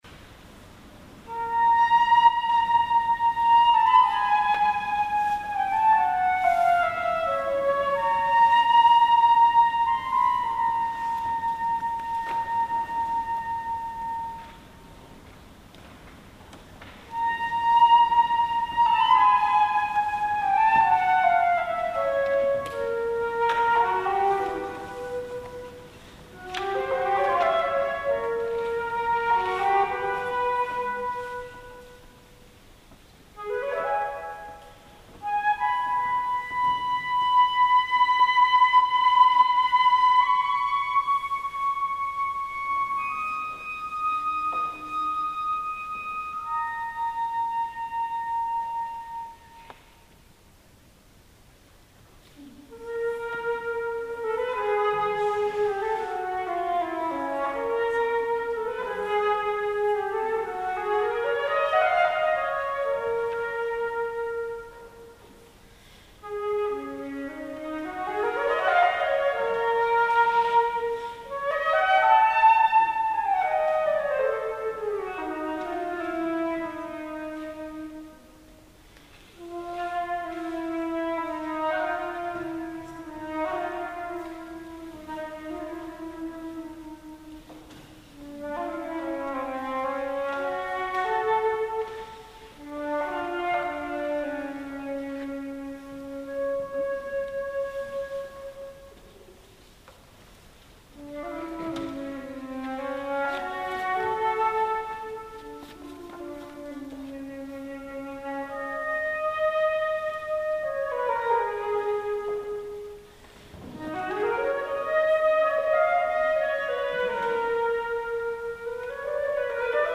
"Syrinx" por fluto (CZ) Ĉi tiu artikolo estis redaktita tiel ke ĝi entenas tutan aŭ partan tradukon de « Claude Debussy » el la germanlingva Vikipedio .
Debussy_solo_Syrinx.mp3